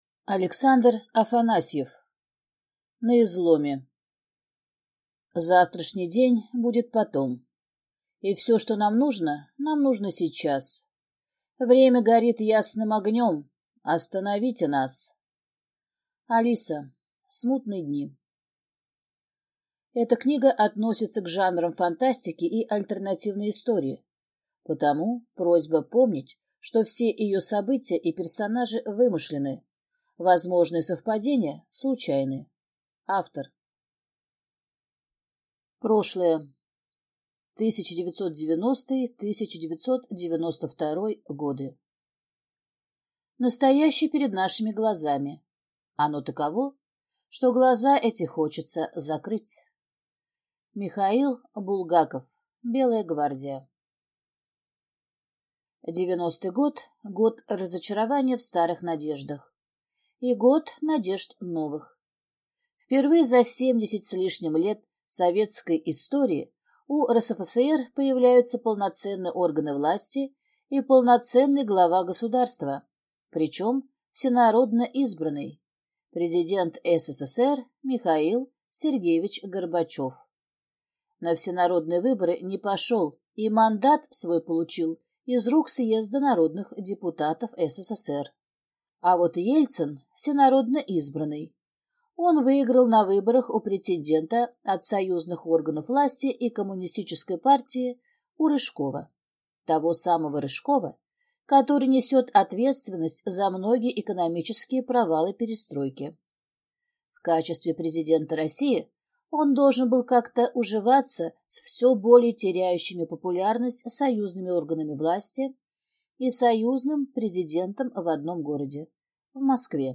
Aудиокнига На изломе Автор Александр Афанасьев Читает аудиокнигу